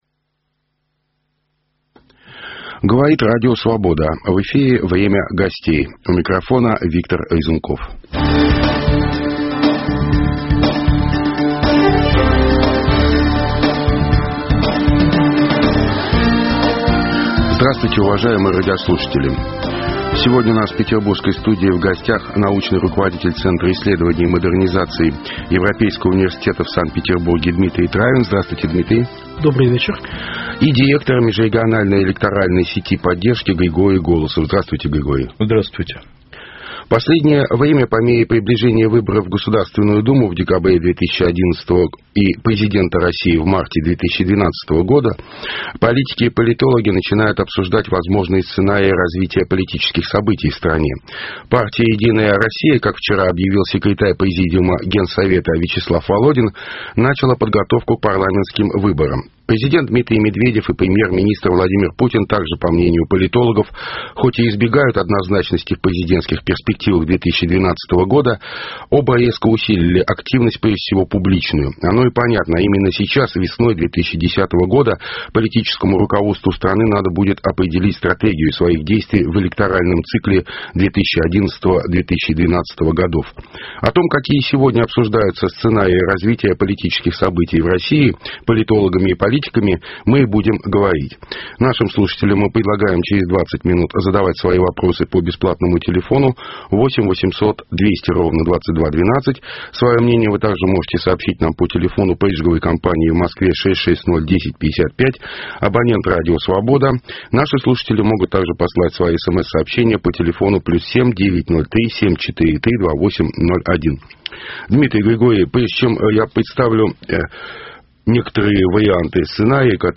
Какие политические сценарии развития событий в стране сегодня обсуждают политики и политологи? В программе беседуют: